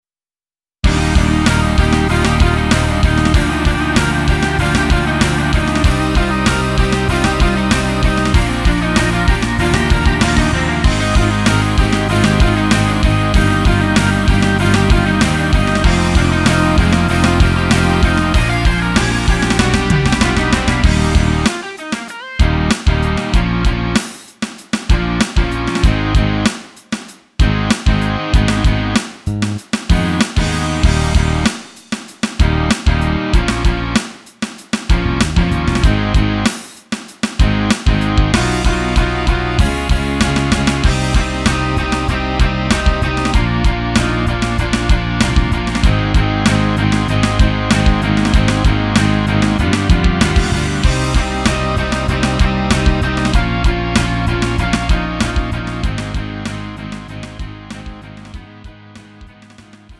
음정 -1키 3:41
장르 가요 구분